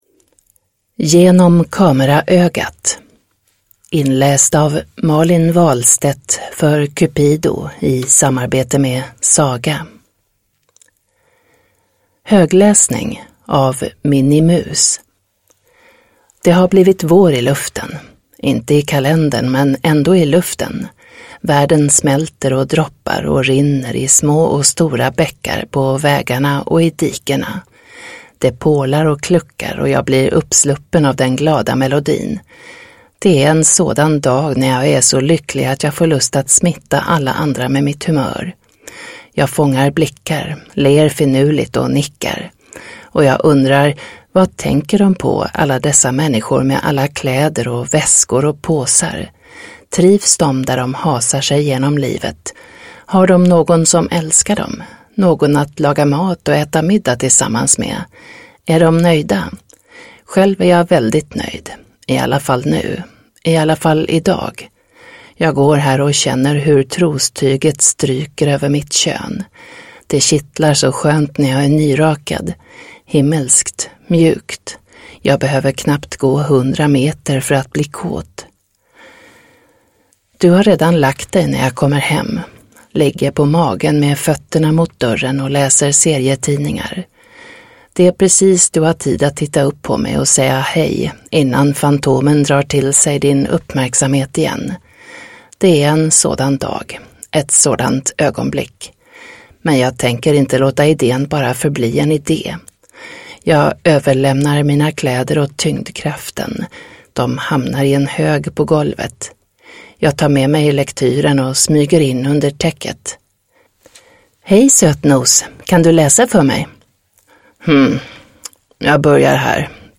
Genom kameraögat (ljudbok) av Cupido